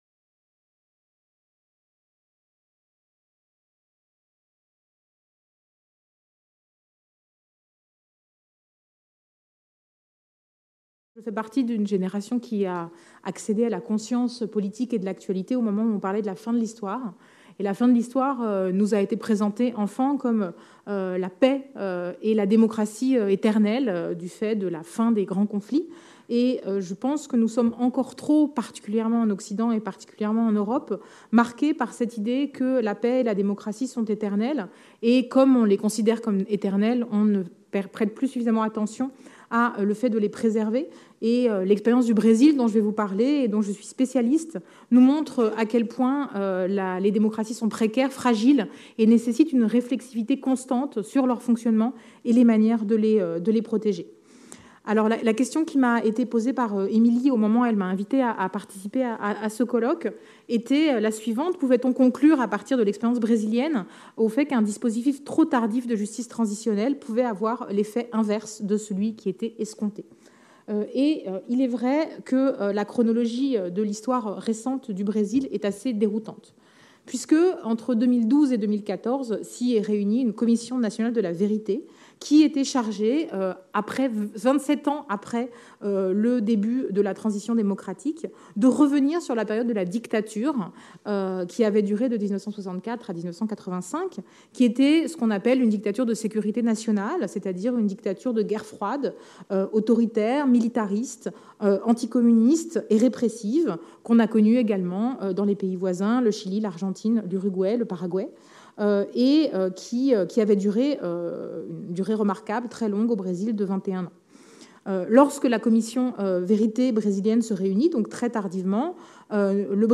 Cette communication a été prononcée dans le cadre du colloque international Justice, Vérité et Résilience(s) qui s'est tenu à Caen les 23 et 24 novembre 2018.